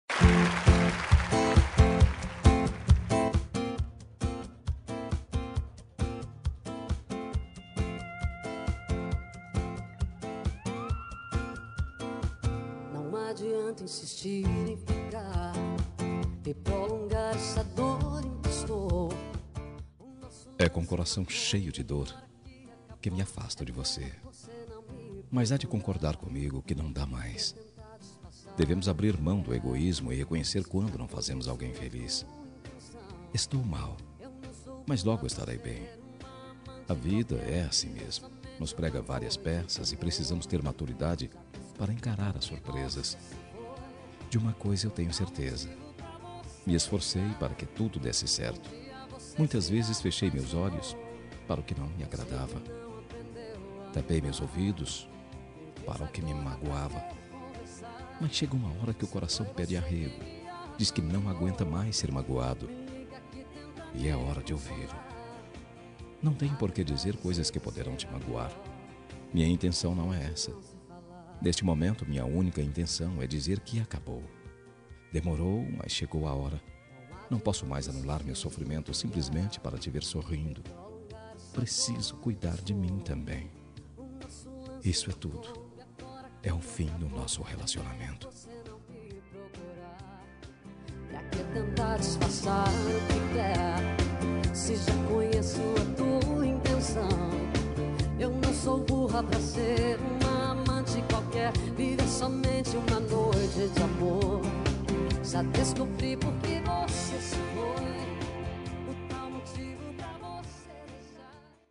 Término – Voz Masculina – Código: 8665
termiono-masc-8665.m4a